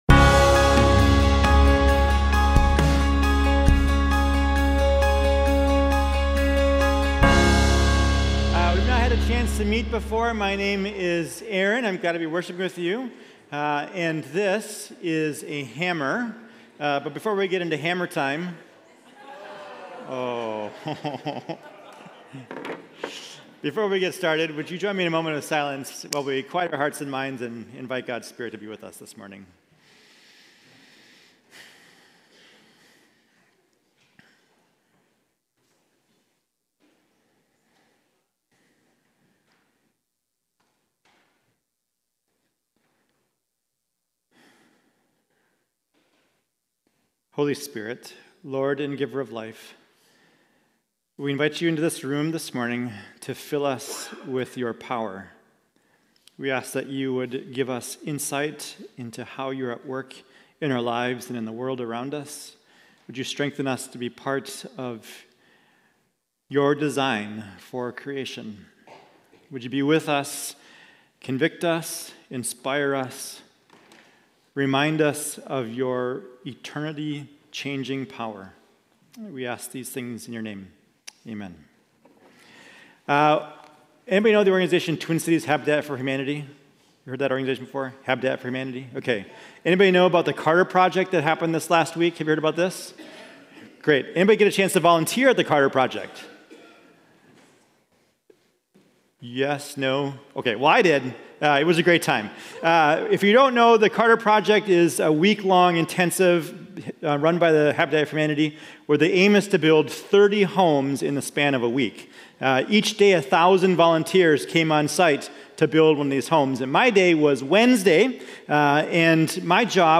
Mill City Church Sermons The Good Life Belongs to the Meek Oct 08 2024 | 00:31:53 1x 00:00 / 00:31:53 Subscribe Share RSS Feed Share Link Embed